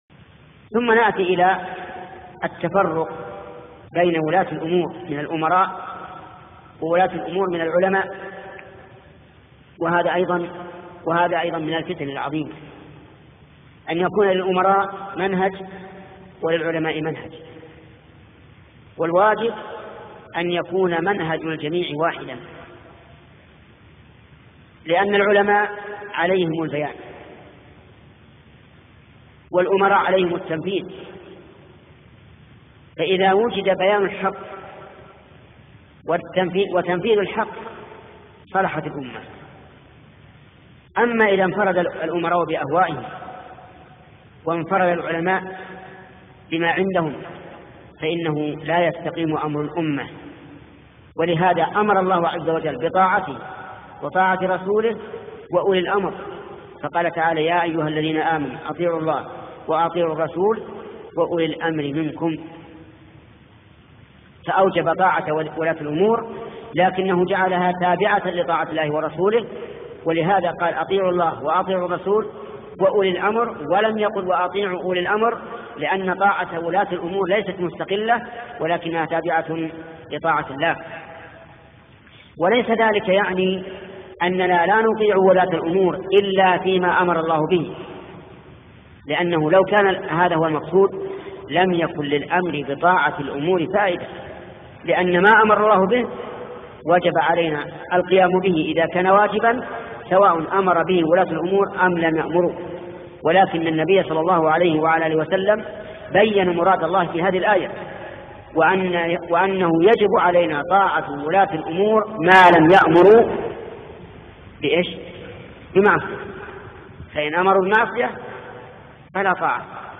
فتاوى من محاضرة